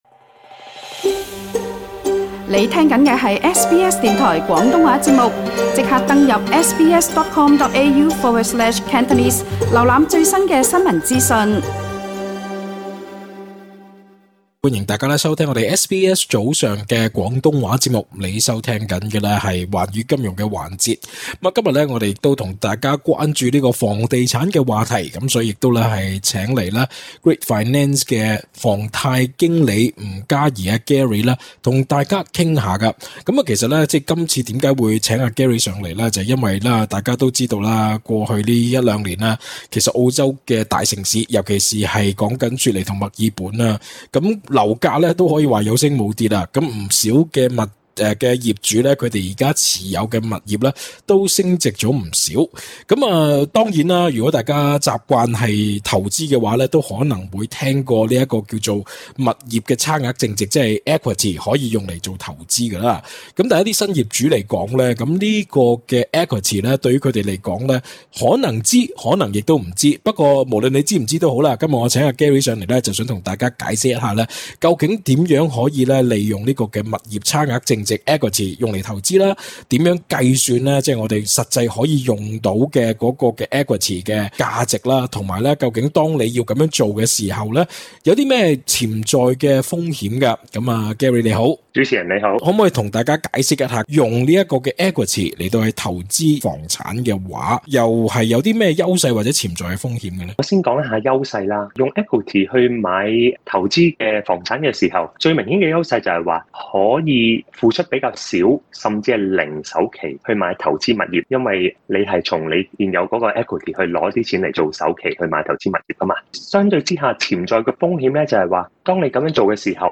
隨著樓價水漲船高，不少業主或正考慮用樓價差額淨值（Equity）買下一個投資物業，SBS廣東話節目訪問了貸款專家，為大家分析想用Equity買投資物業策略前要注意的事項。